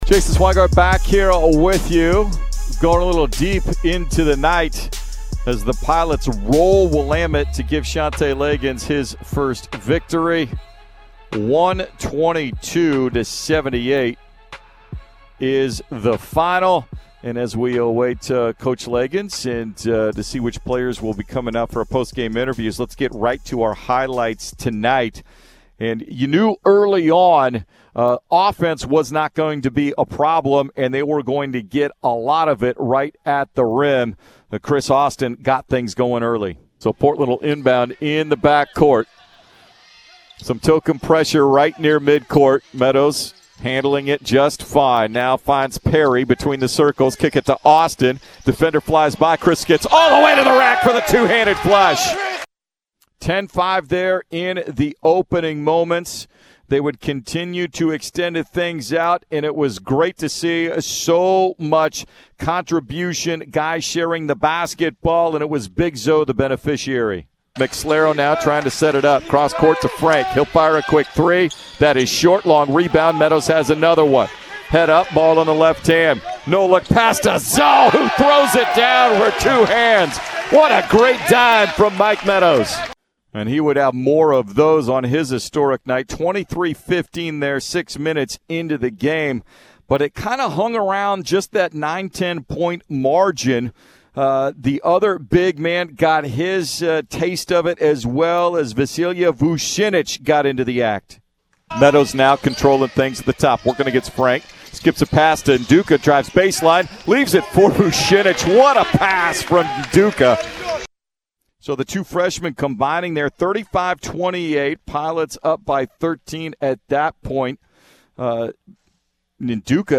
Men's Basketball Radio Highlights vs. Willamette